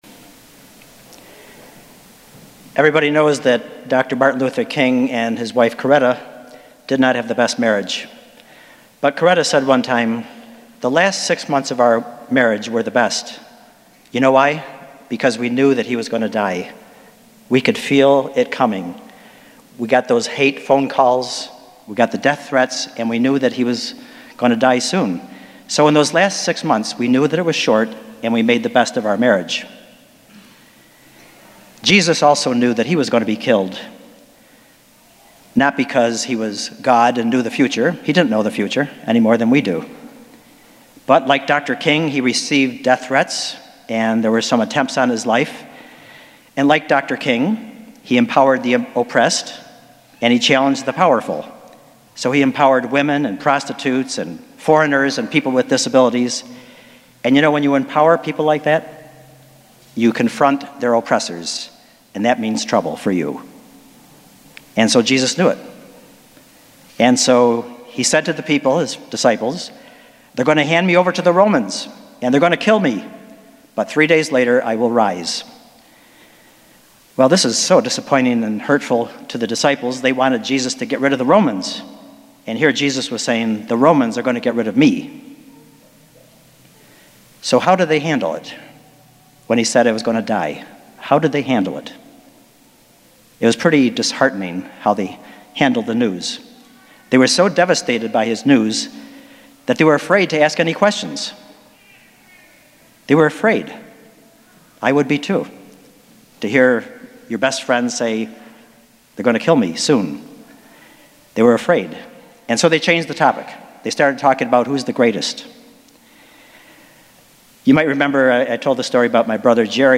Spiritus Christi Mass September 23rd, 2018